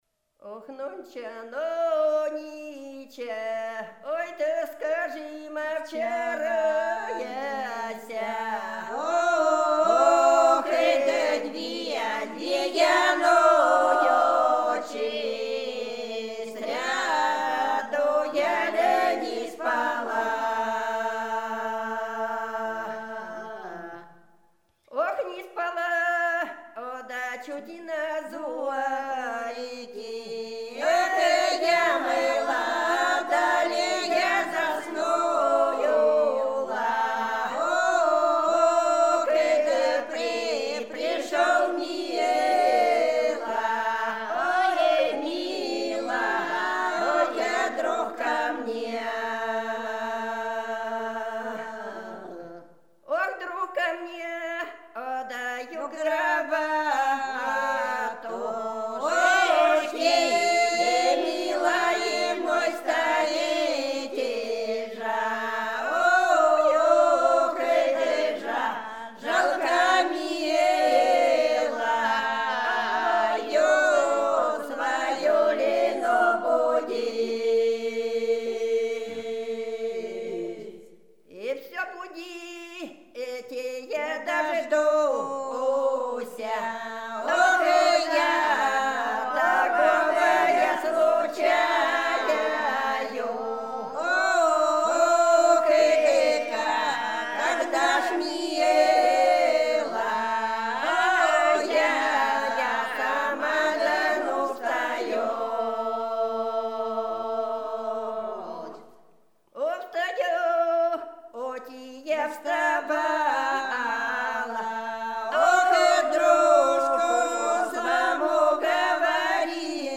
Рязань Секирино «Нонча, нонча и вчерася», лирическая.